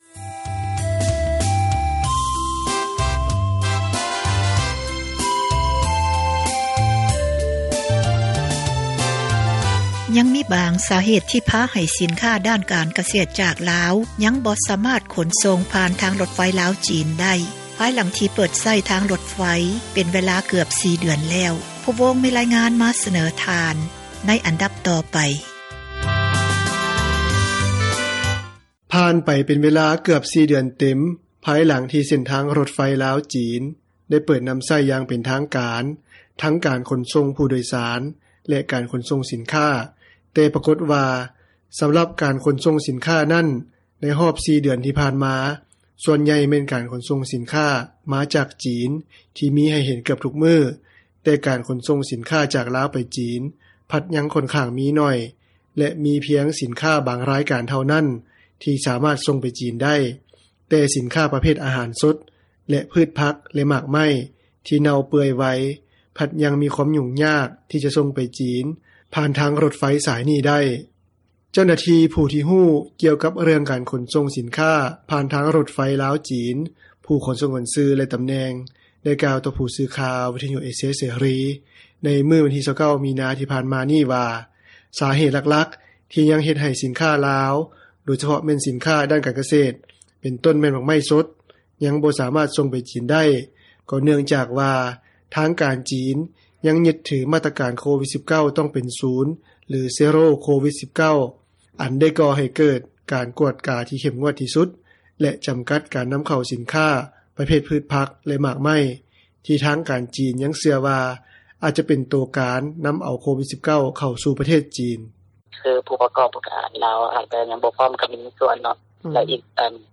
ສົ່ງໝາກໄມ້ສົດລາວ ຜ່ານຣົຖໄຟລາວ-ຈີນ ຍັງຫຍຸ້ງຍາກ — ຂ່າວລາວ ວິທຍຸເອເຊັຽເສຣີ ພາສາລາວ